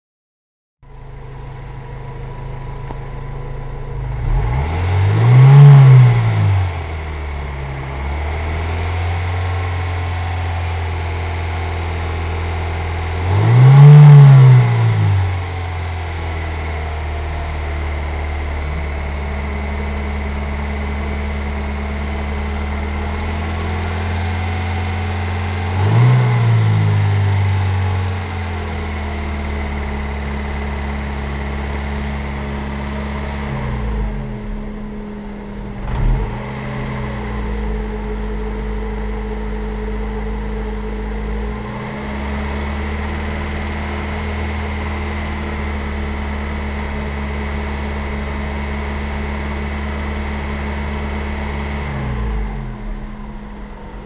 Das Geräusch was im Innenraum bei den gennanten Drehzahlen kommt ist bei 0:42 bis Ende gut zu hören.
Warm
hört sich ganz normal an